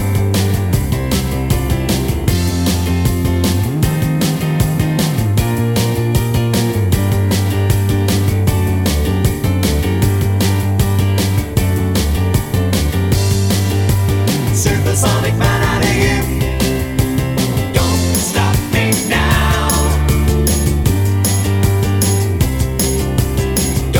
No Lead Guitars Rock 3:43 Buy £1.50